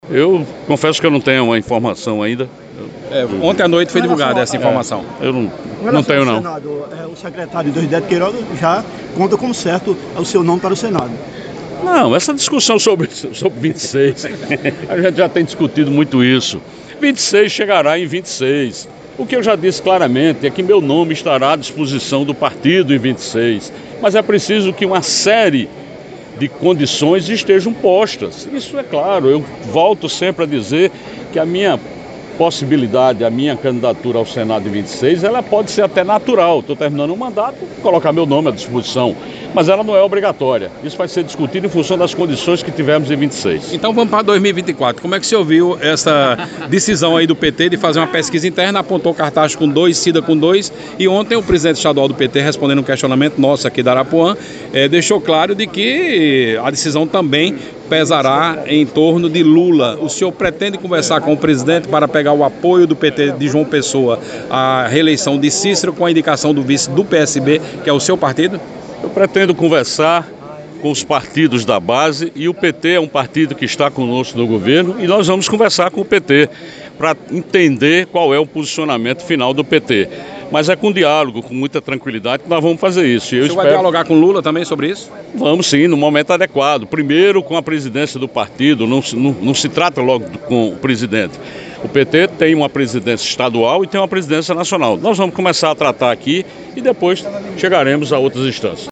Em resposta, João Azevêdo afirmou que pode sim procurar Lula para construir o apoio.